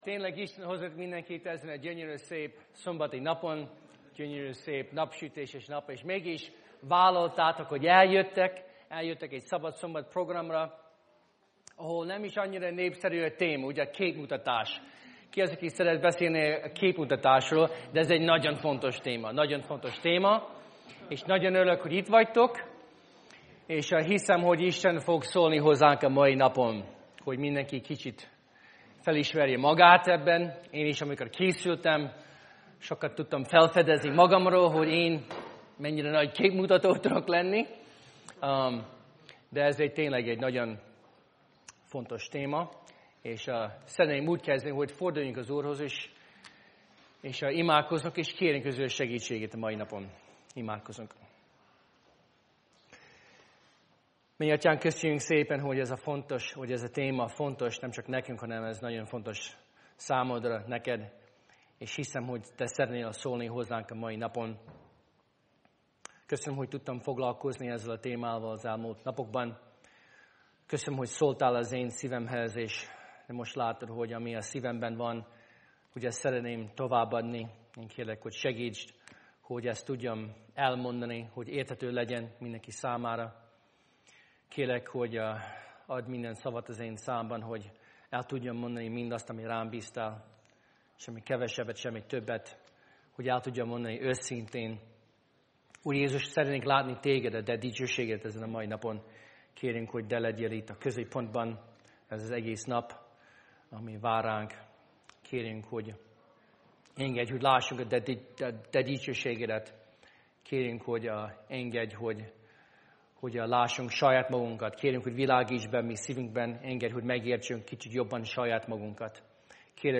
Előadások 2015-ben